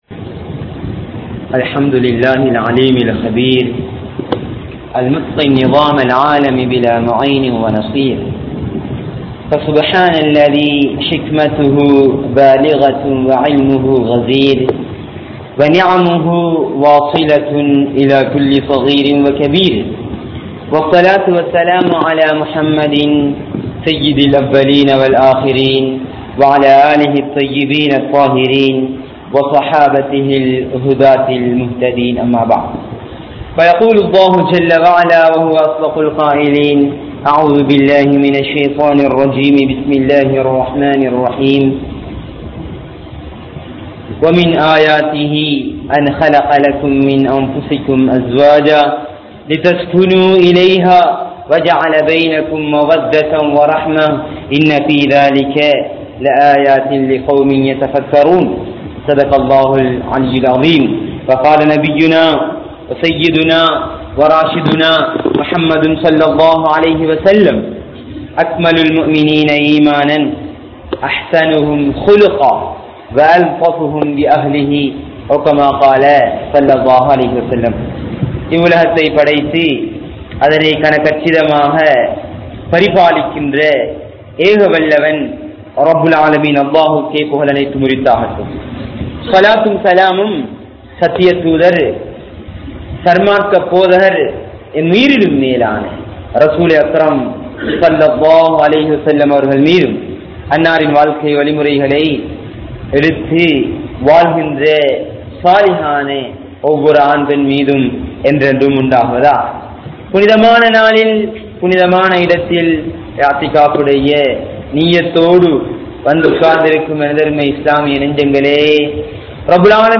Nimmathi Attra Kanavanum Manaivium (நிம்மதியற்ற கணவனும் மணைவியும்) | Audio Bayans | All Ceylon Muslim Youth Community | Addalaichenai